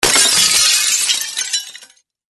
Звуки стекла